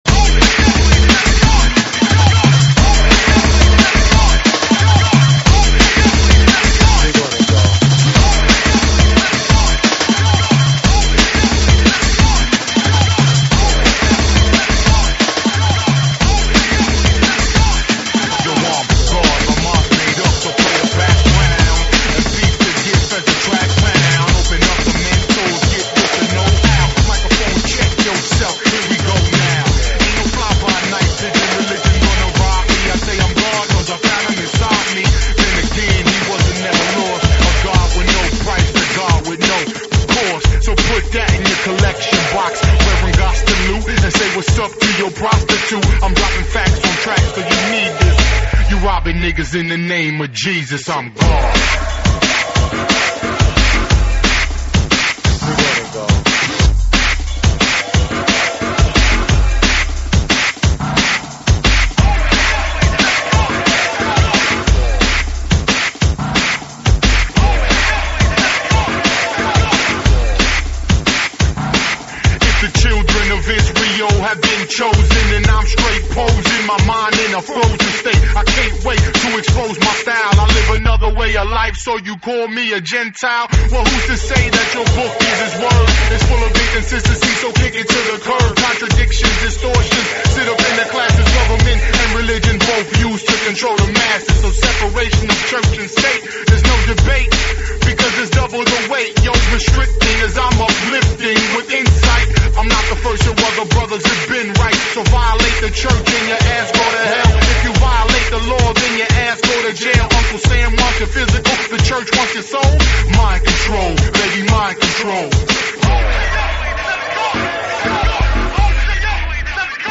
Drum&Bass, Dubstep